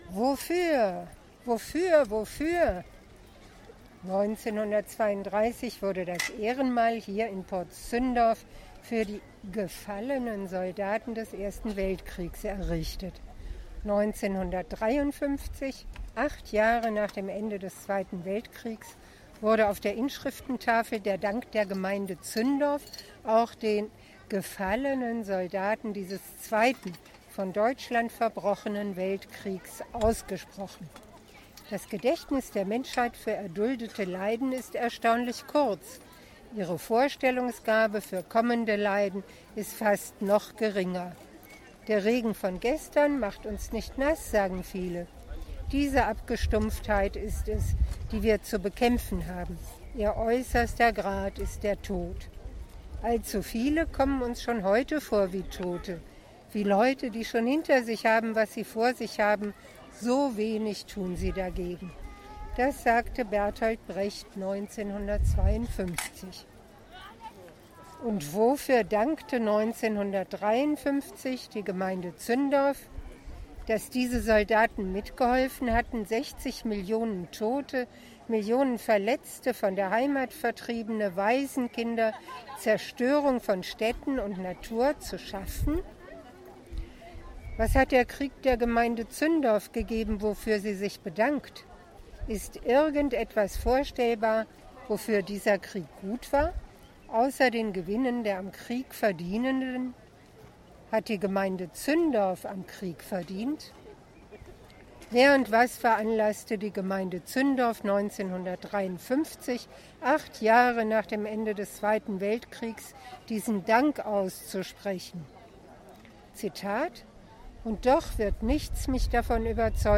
Zum Inhalt der Kunstaktion und Statements im O-Ton finden sich hier!